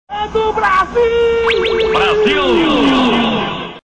"É DO BRASIL", narração de Galvão Bueno
galvao-bueno-e-do-brasil.mp3